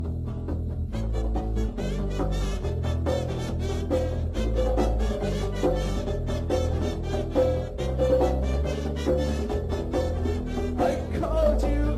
Pop & Rock
Goth Kazoo Goth